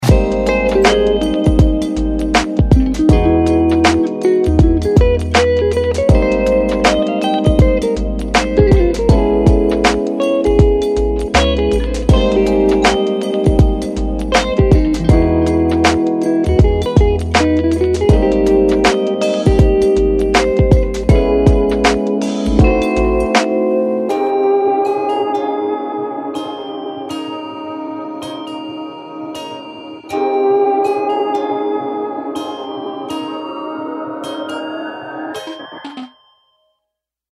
Dive into a collection of smooth jazz guitar loops, dreamy synth melodies, and soulful keys that capture the essence of lo-fi hip-hop and chillout music.
From mellow jazz guitar riffs to lush synth pads and vintage keys, each loop is infused with character and authenticity.
Lo-Fi-Grooves-Vol-1.mp3